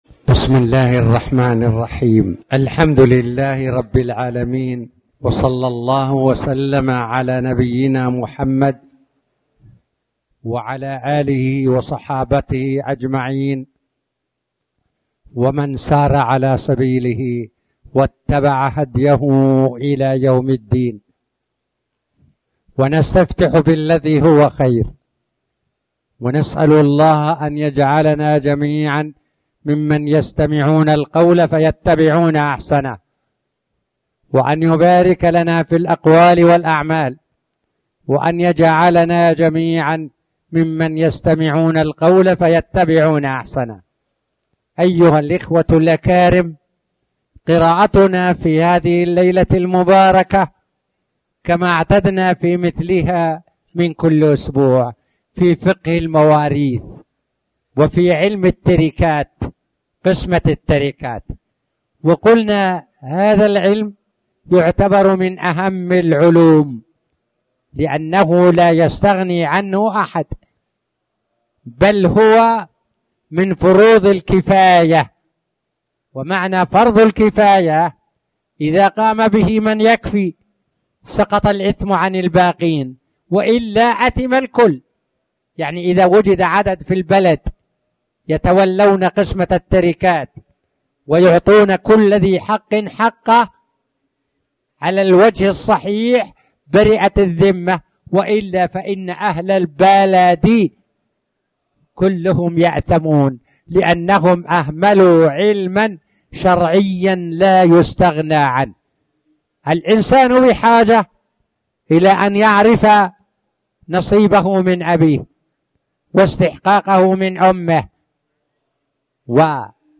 تاريخ النشر ٥ محرم ١٤٣٩ هـ المكان: المسجد الحرام الشيخ